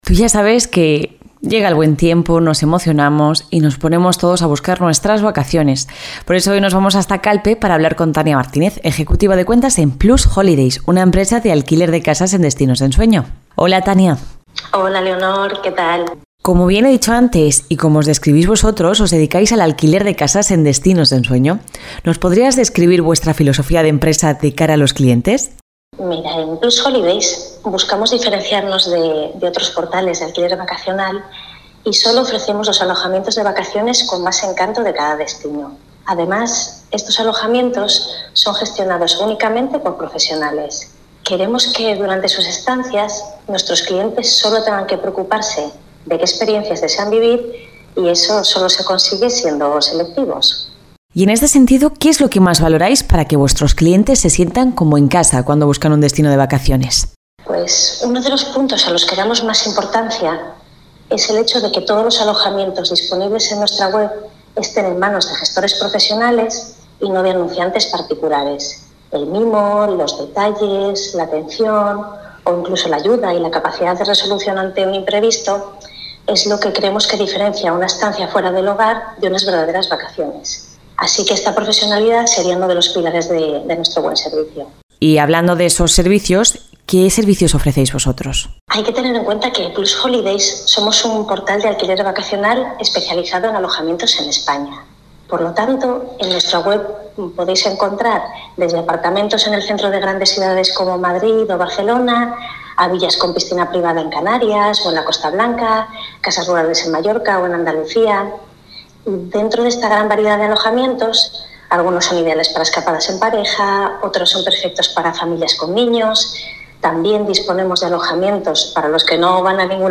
ENTREVISTA-PLUSHOLIDAYS.mp3